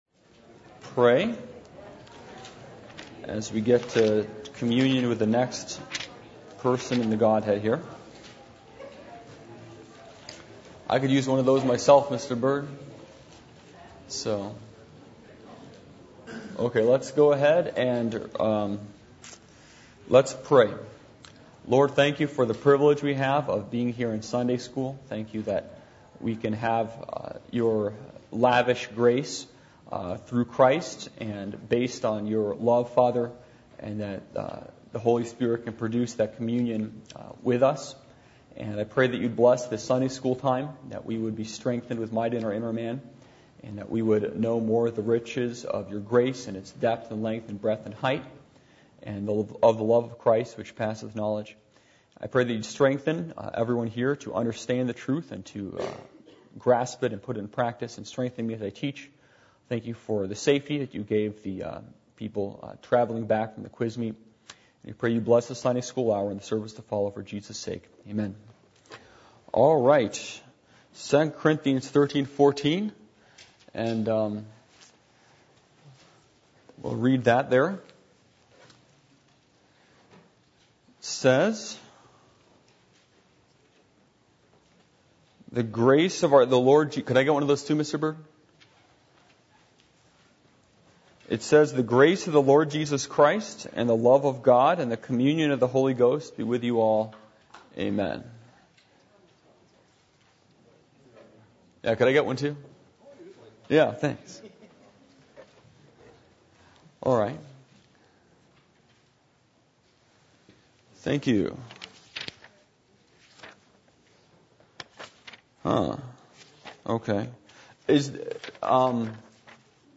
Series: Studies on the Trinity Service Type: Adult Sunday School %todo_render% « What Will Bring You True Satisfaction?